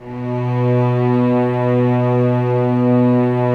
Index of /90_sSampleCDs/Roland LCDP13 String Sections/STR_Vcs II/STR_Vcs6 mf Amb